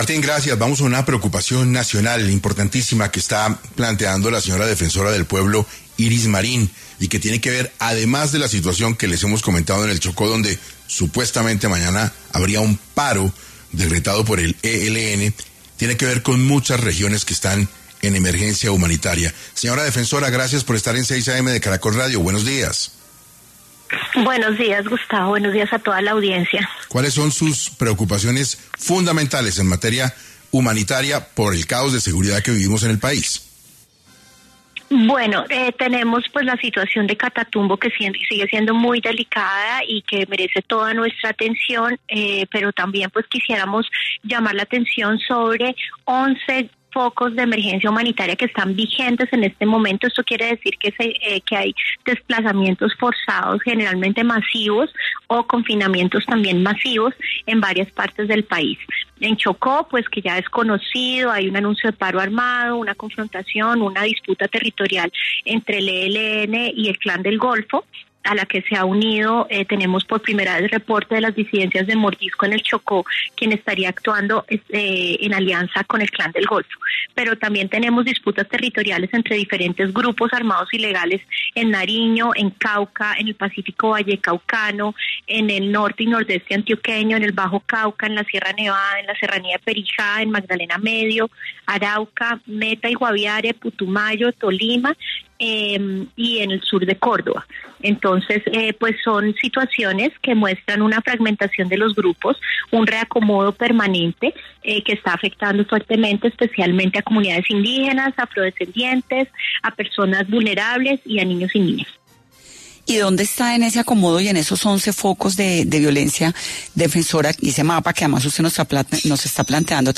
En diálogo con Caracol Radio, la defensora del pueblo Iris Marín, habló sobre la situación de violencia generalizada que vive el país.